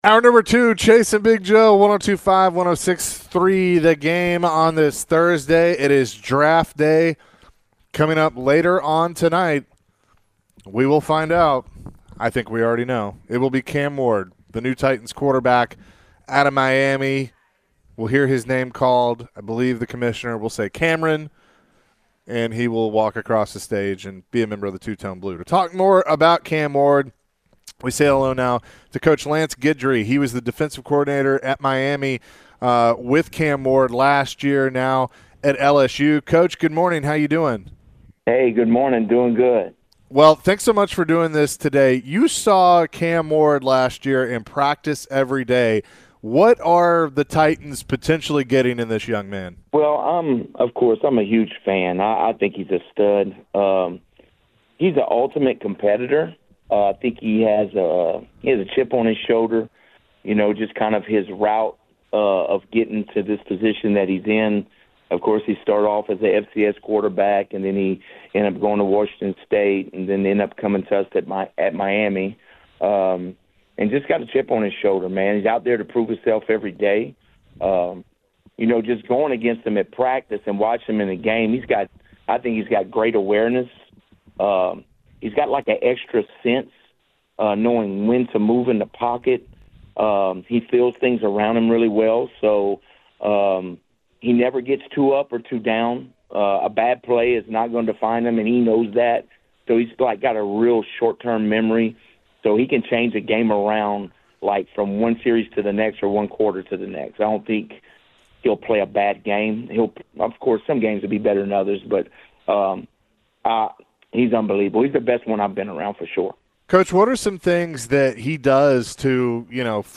Later in the hour, NASCAR Driver Noah Gragson joined the show. Noah discussed the in-depth analysis of driving in NASCAR. Plus, Noah mentioned his strategy when it comes to racing.